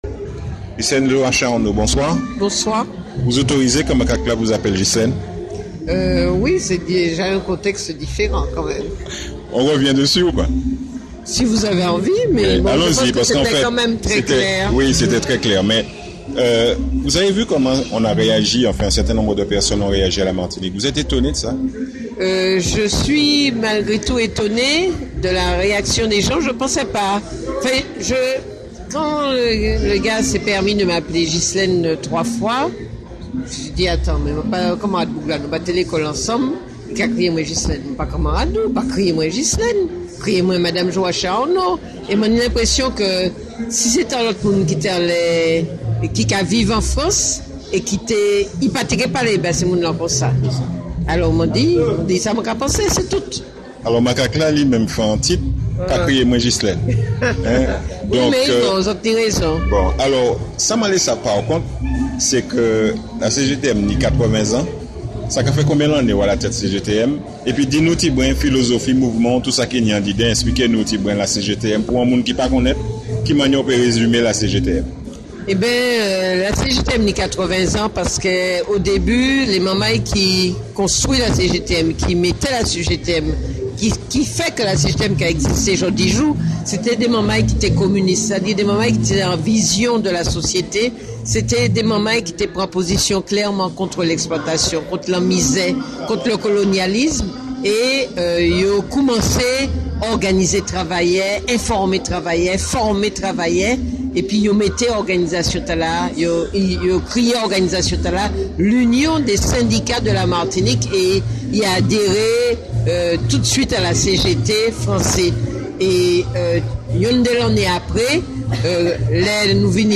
Une ITW de 12 minutes qui constitue une alternative comme complément d’information vis-à-vis de d’autres types de médias dont le formatage ne permet pas de diffuser des ITW aussi longues.